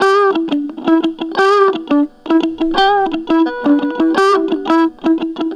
Track 02 - Guitar Lick 03.wav